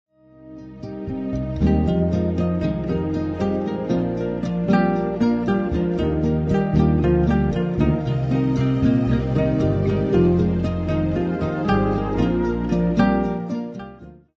Instrumental Album of the Year